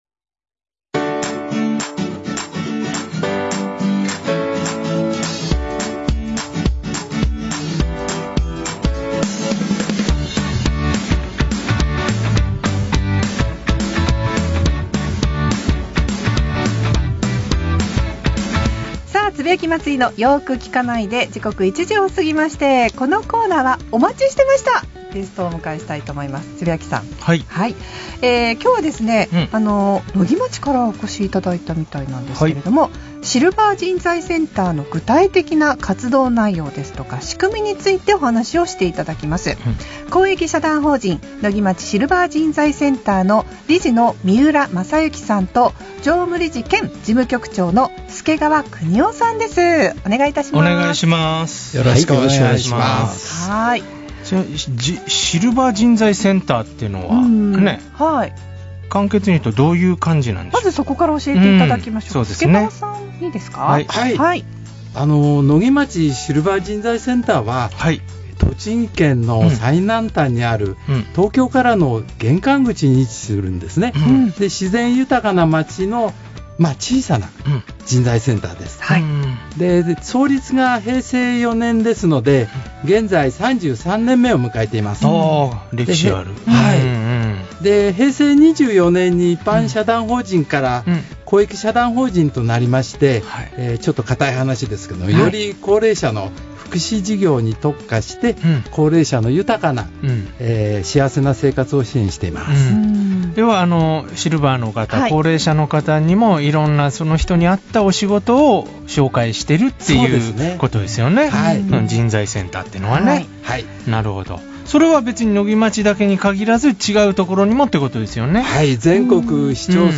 栃木放送ラジオに生出演しました!!
野木町SCで活躍中の会員さんが栃木放送ラジオ番組に生出演し、シルバー人材センターの紹介や、シルバーの仕事を通して体験したエピソードを楽しくお届けしてくれました。